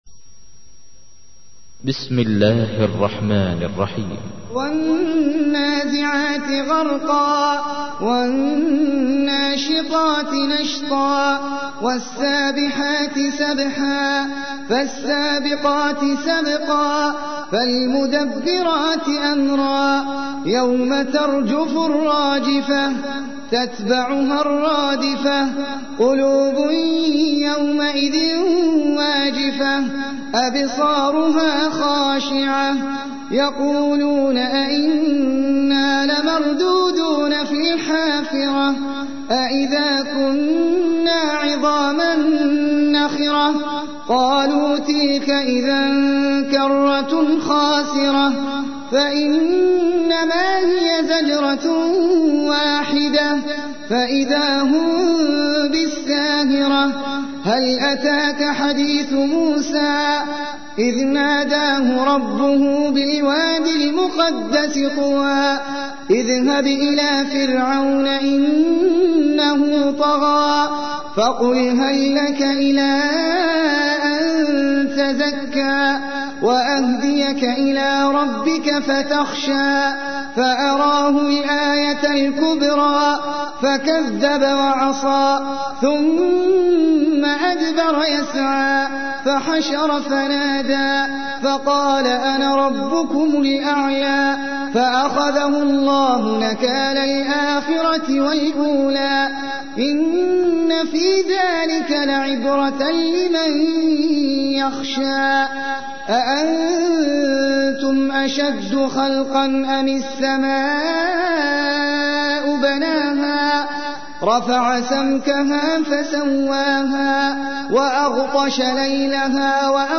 تحميل : 79. سورة النازعات / القارئ احمد العجمي / القرآن الكريم / موقع يا حسين